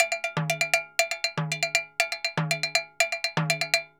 Timbaleta_Baion 120_1.wav